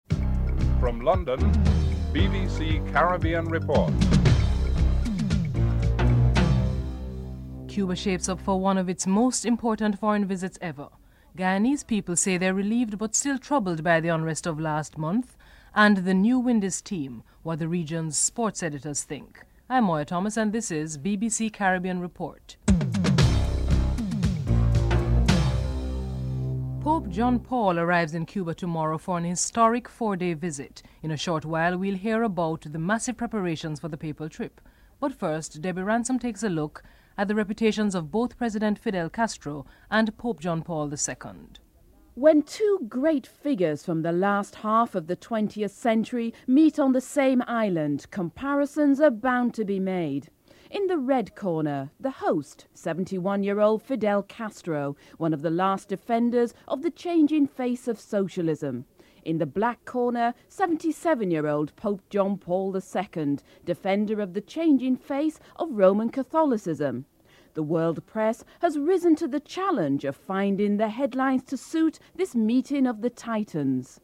The people of Guyana express their views on the agreement which ended the political strife. The European Commission Agriculture Ministers held the first debate in Brussels on changing the banana import regime to make it WTO compatible.
The new skipper, Brian Lara comments on the team and the need for support.